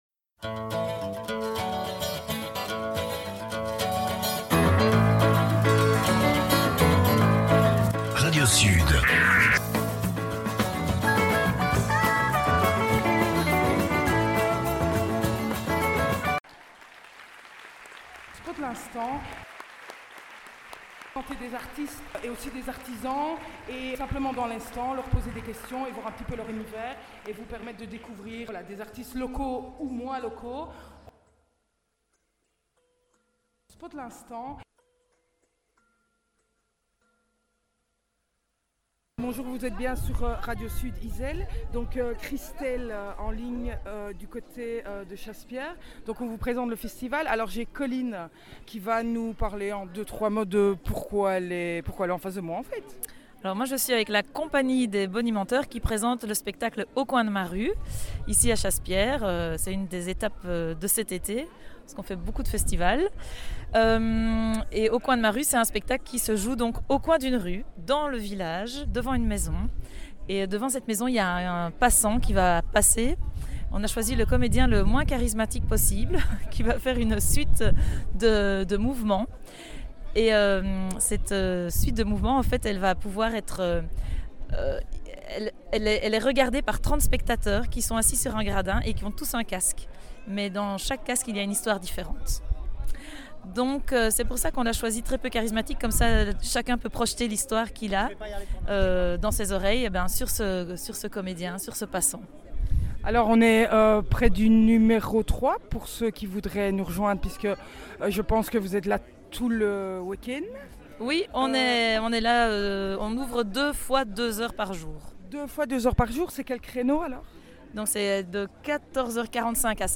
Spot'L'instant rencontre la Compagnie des Bonimenteurs lors du 51e festival international des arts et de la rue de Chassepierre.